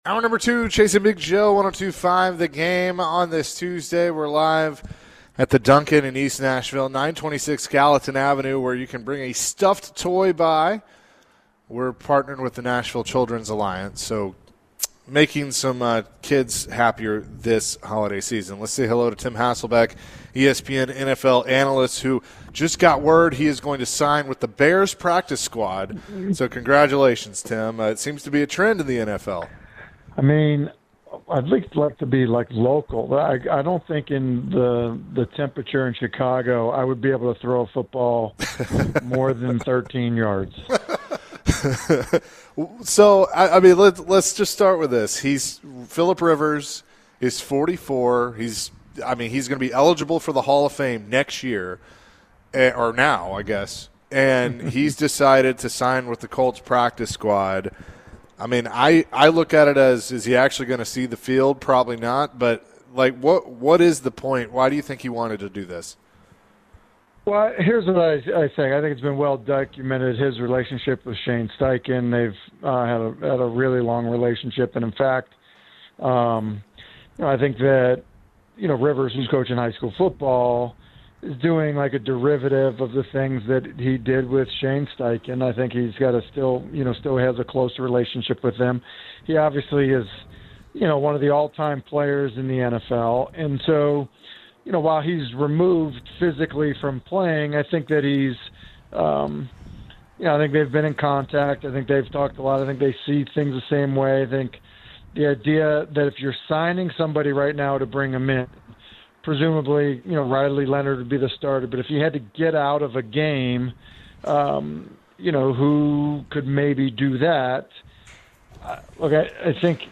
the guys speak with Tim Hasselbeck about Philip Rivers signing with the Colts, the unfairness of the Cam Ward vs. Shedeur Sanders debate, and Diego Pavia.